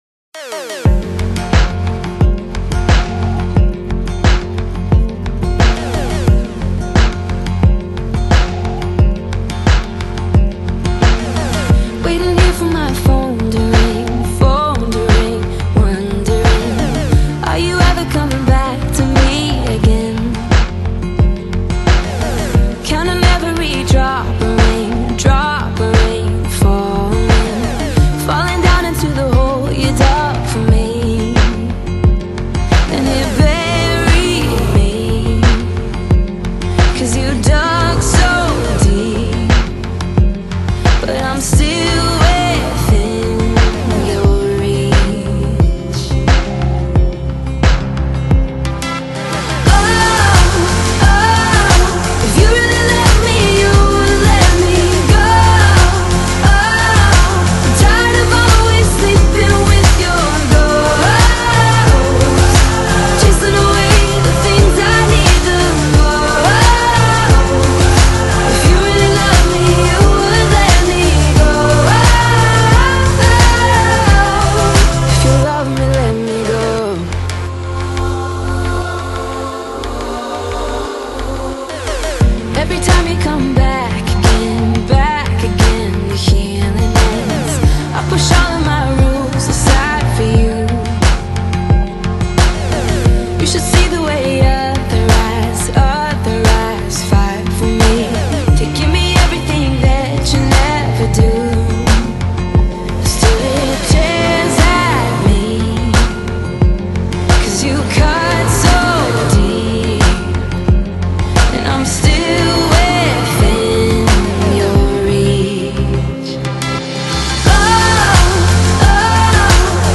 Pop | MP3 CBR 320 kbps | 49:18 min | 120 MB + 5% Recovery